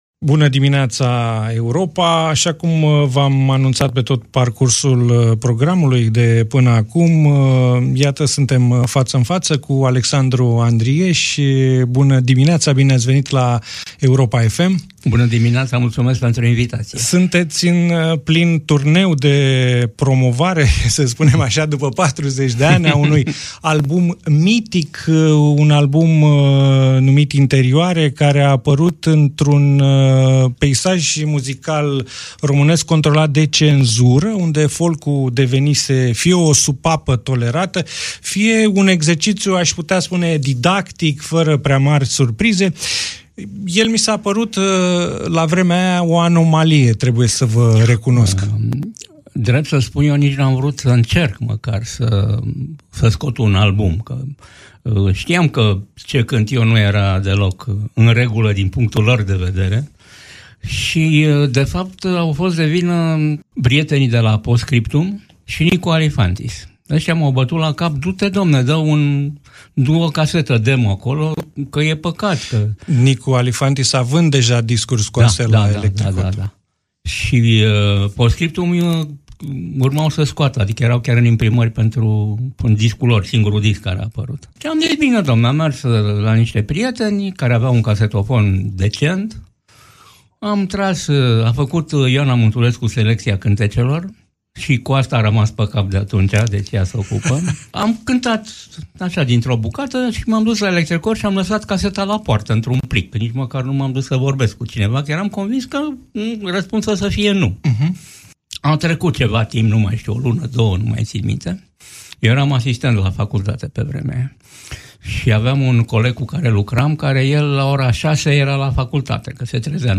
Alexandru-Andries-Interviu-112.mp3